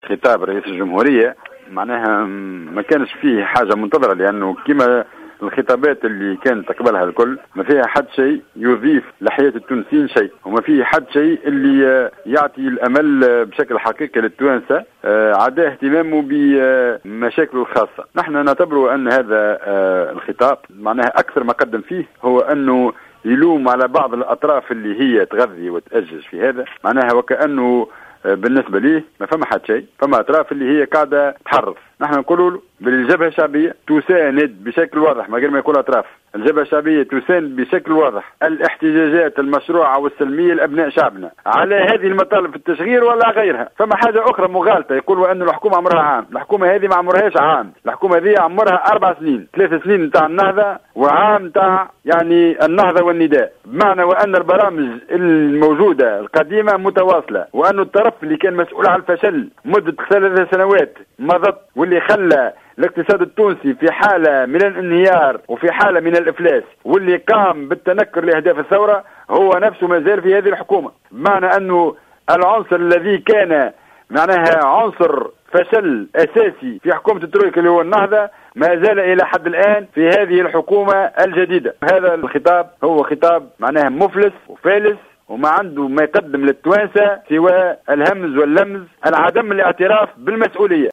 ووصف الرحوي، في تصريح هاتفي للجوهرة "اف ام" ، هذا الخطاب بـ"المفلس"، منتقدا تناول قائد السبسي لمسألة الاحتجاجات فقط من ناحية محاولة بعض الأطراف لـتأجيجها، دون العودة إلى الأسباب الاجتماعية التي دفعت الشباب إلى الاحتجاج، مشددا على مساندة الجبهة الشعبية للاحتجاجات السلمية.